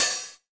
9HH OPN.wav